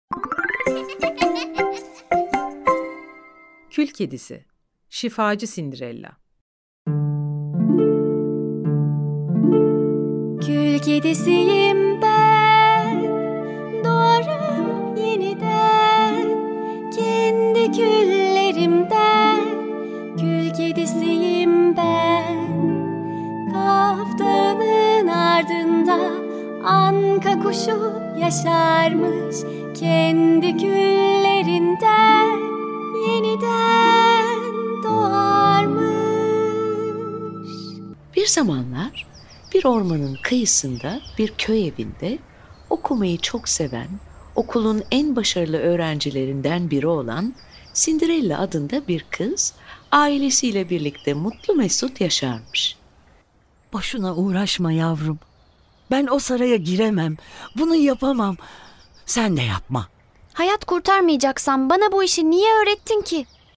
Kül Kedisi-Şifacı Sindirella Tiyatrosu